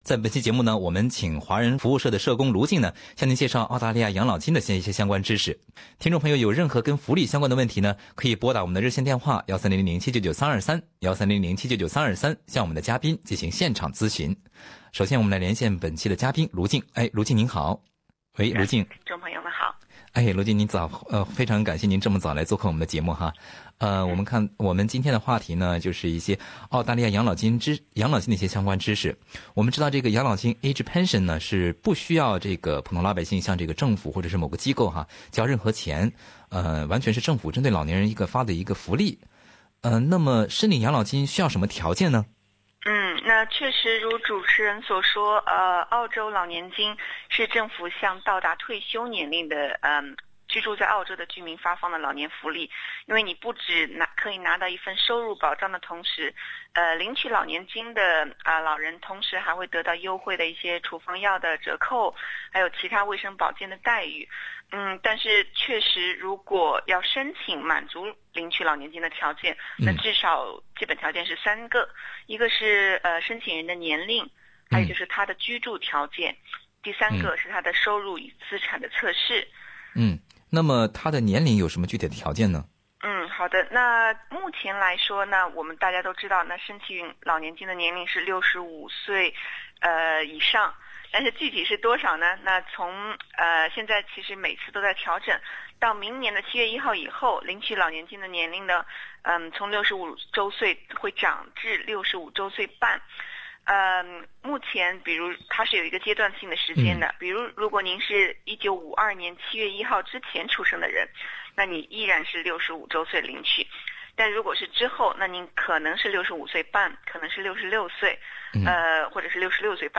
那么领取养老金需要满足什么条件呢？可以领到多少钱呢？本期《澳洲福利知多少》听众热线节目